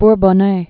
(br-bôɴ-nā)